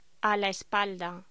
Locución: A la espalda
voz
locución